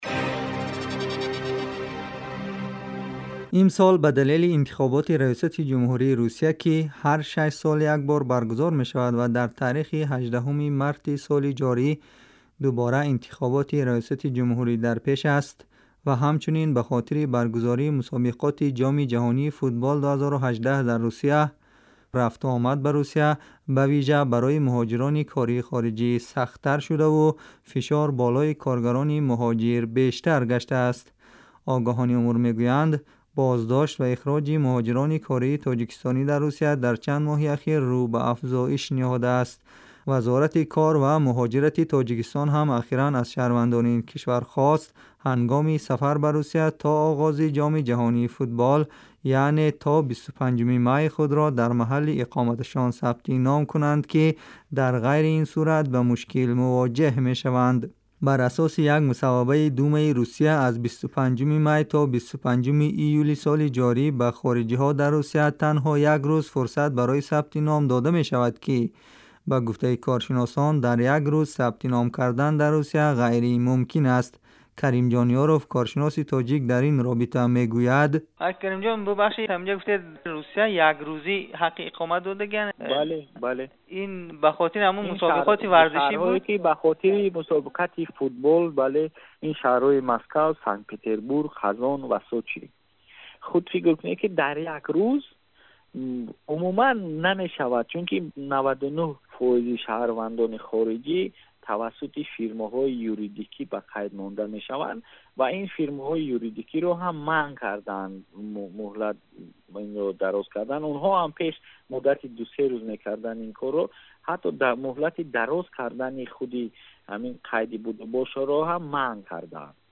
гузорише вижа